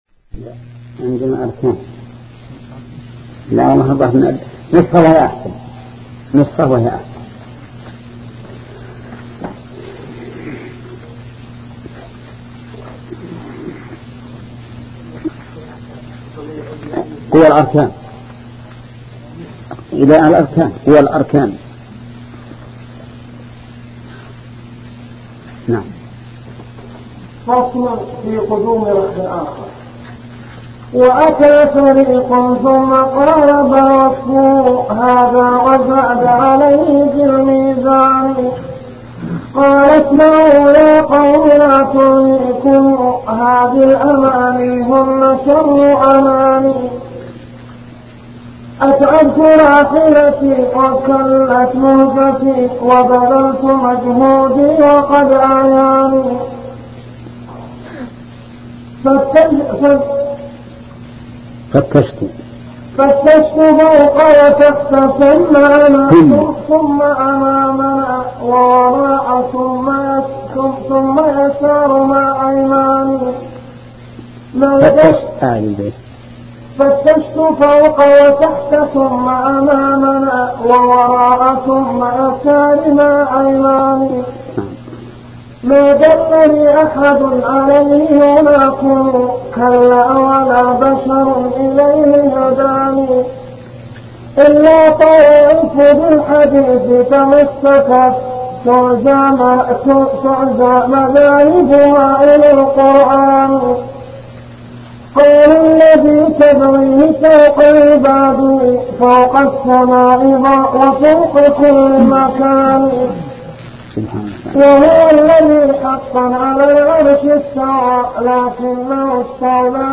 شبكة المعرفة الإسلامية | الدروس | التعليق على القصيدة النونية 5 |محمد بن صالح العثيمين